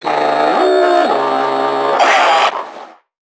NPC_Creatures_Vocalisations_Infected [79].wav